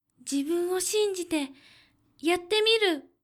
ボイス
キュート女性
josei_zibunwoshinziteyattemiru.mp3